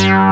MOOGBAS4.wav